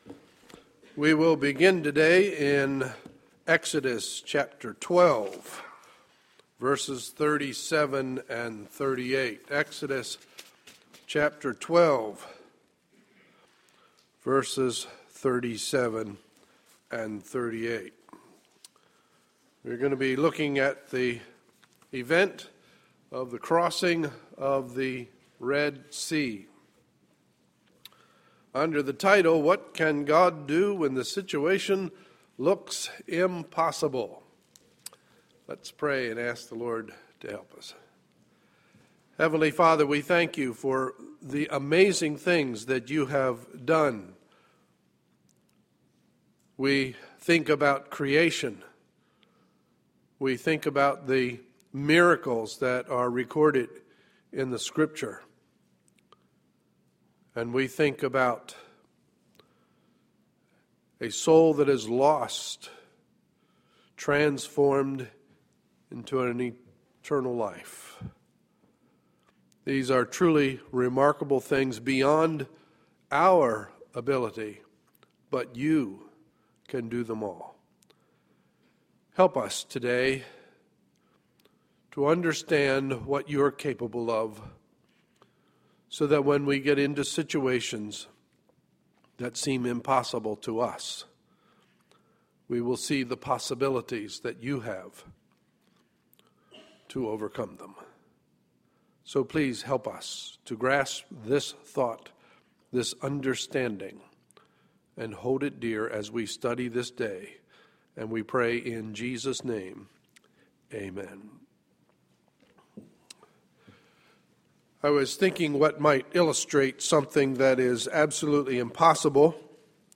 Sunday, March 17, 2013 – Morning Message